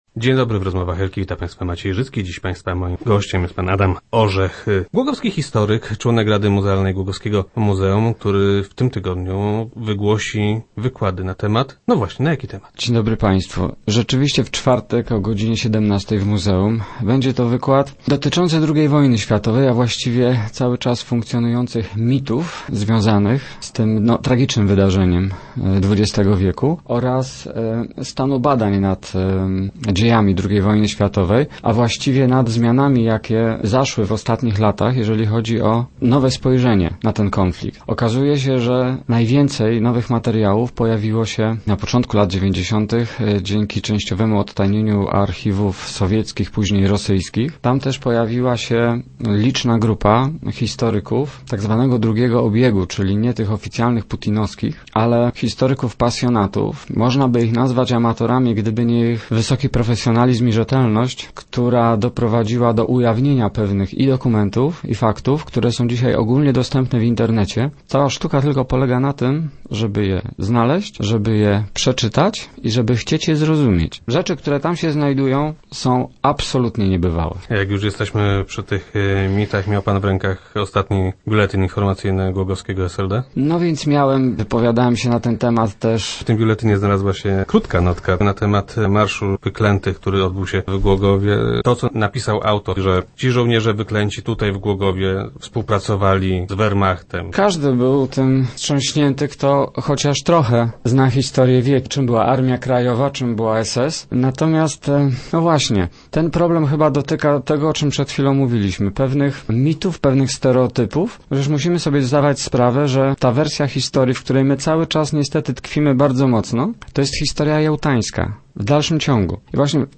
Wywiad bardzo ciekawy.